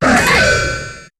Cri d'Exagide dans Pokémon HOME.